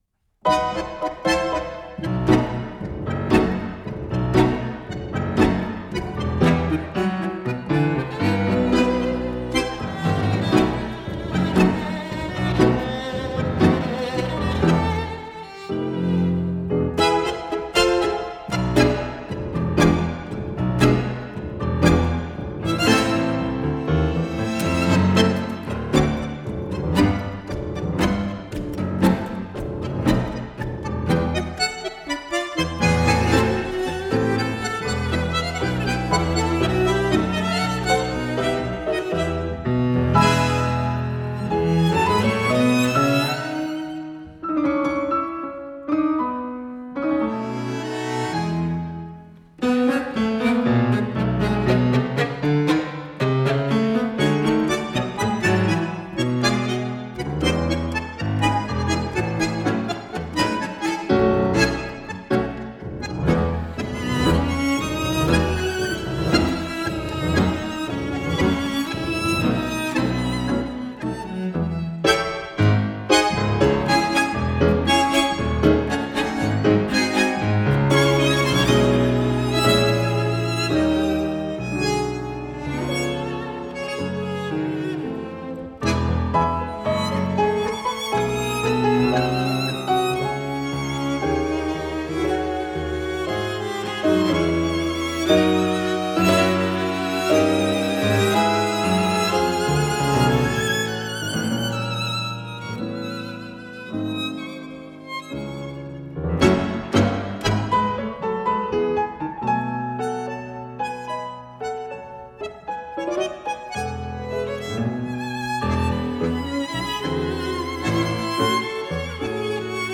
chamber-sized ensemble.
Genre: Tango